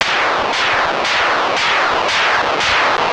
There are four different sounds produced by the game.